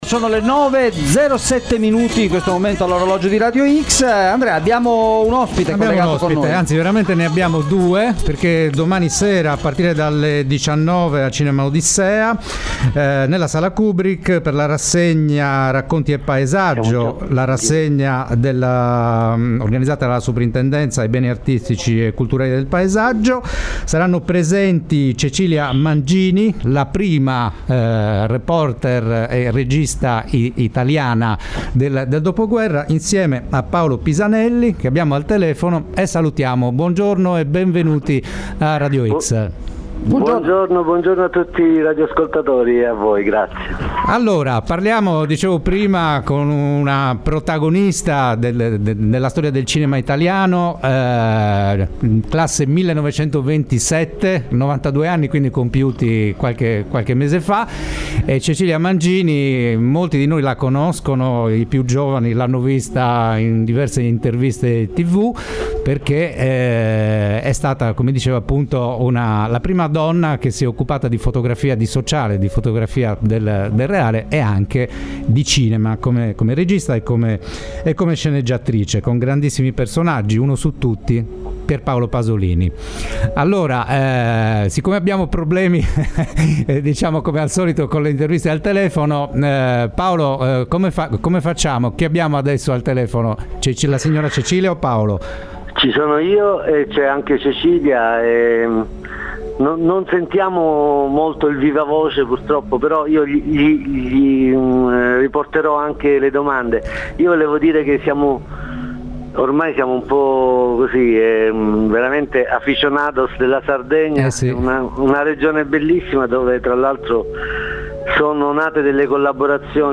All’Odissea “Le Vietnam sera libre” – intervista